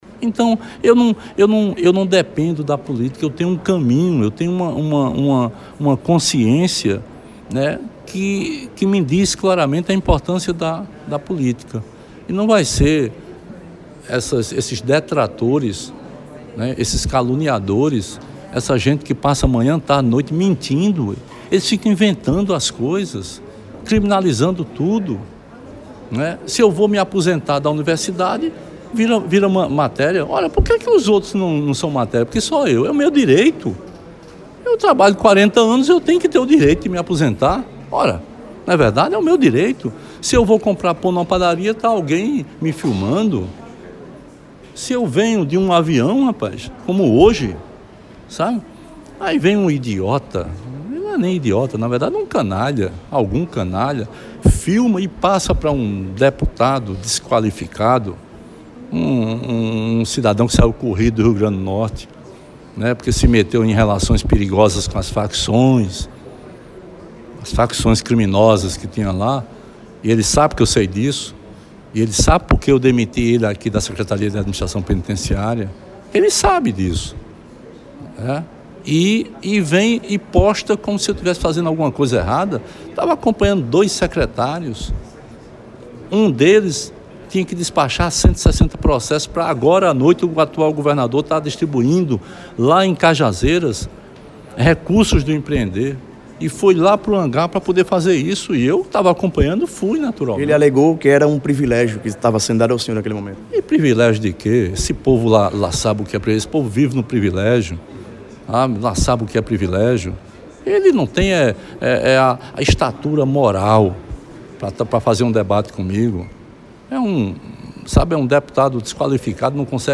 O ex-governador Ricardo Coutinho (PSB) rebateu críticas e atacou o deputado estadual Wallber Virgolino (Patriotas), durante entrevista ao “Programa Panorama 92” e ao Blog do Felipe França, na noite da última sexta-feira (26), no Campus IV da UFPB, em Mamanguape.
Fala de Ricardo: